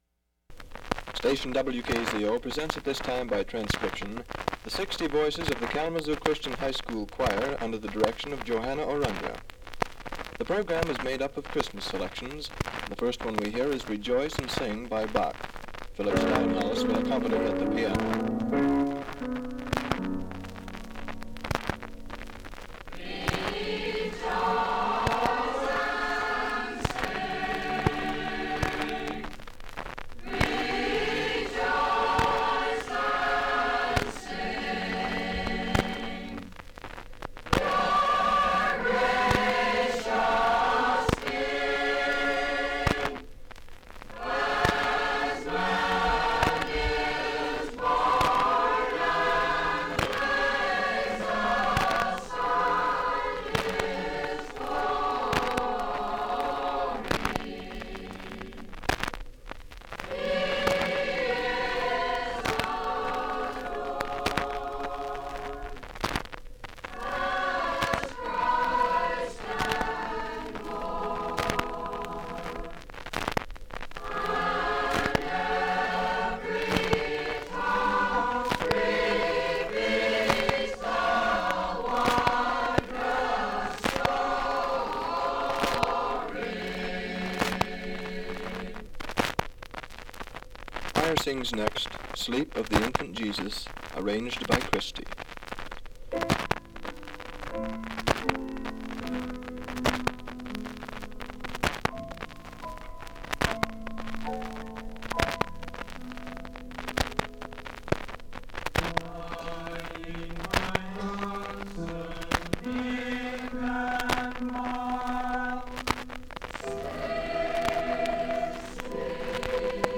Kalamazoo Christian High School choir and Kalamazoo Christian Girls Glee Club perform Christmas carols